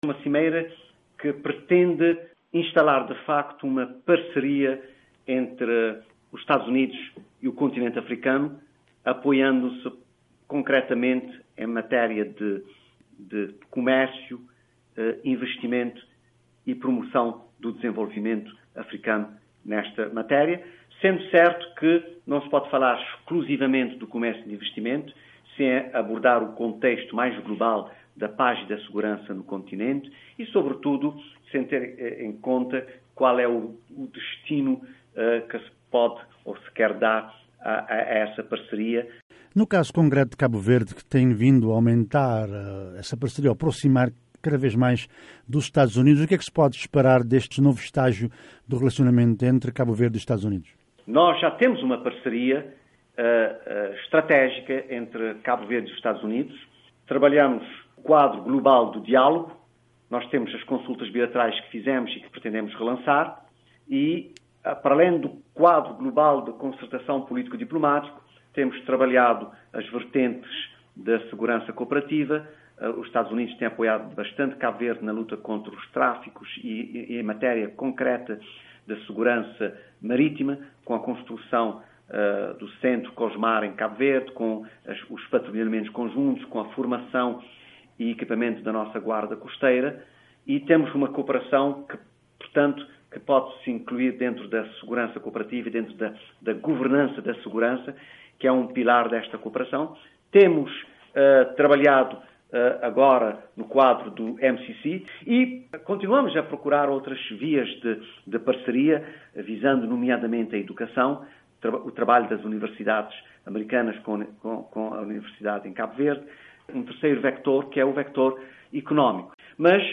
VOA entrevista embaixadores africanos sobre a cimeria EUA/África.
José Luís Rocha, Emb.Cabo Verde nos EUA fala sobre Cimeira 2:34